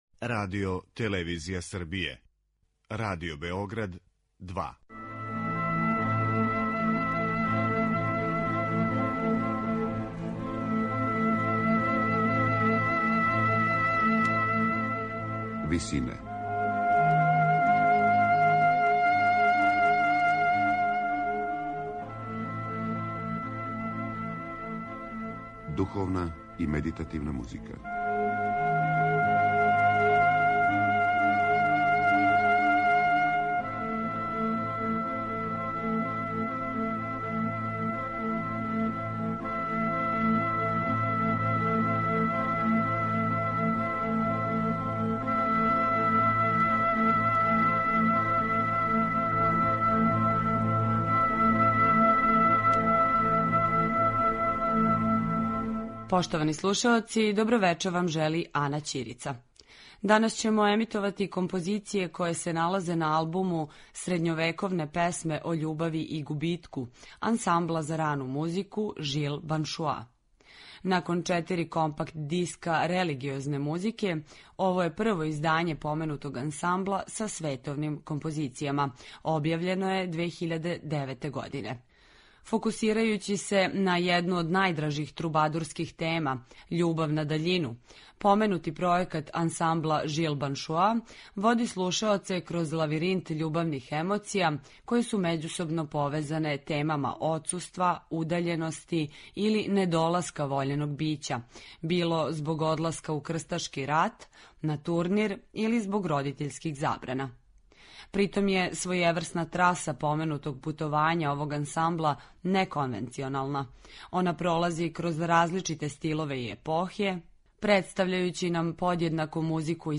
ансамбл за рану музику